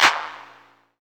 VEC3 Claps 007.wav